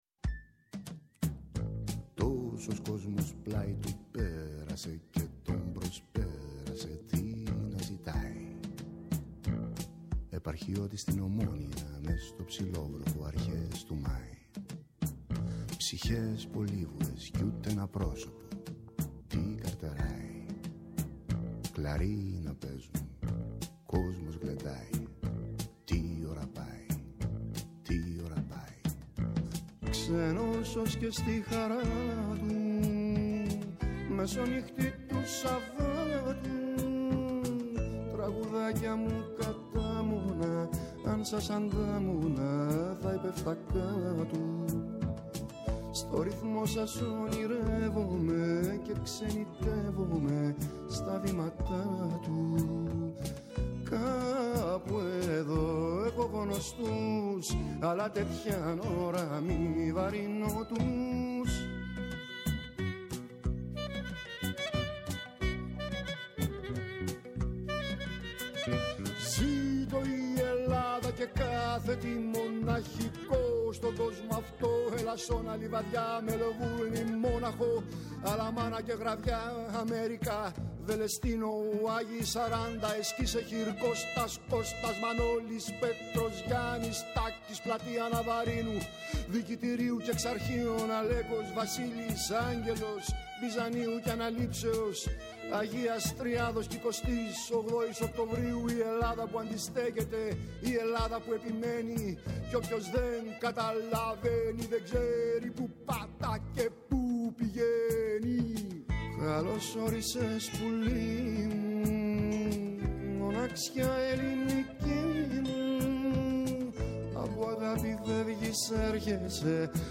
Καλεσμένη στο στούντιο της εκπομπής η Ειρήνη Αγαπηδάκη, ψυχολόγος και επικεφαλής στο ψηφοδέλτιο Επικρατείας της Νέας Δημοκρατίας